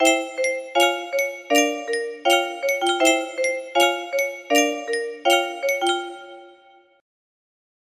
1,024 (fixed) music box melody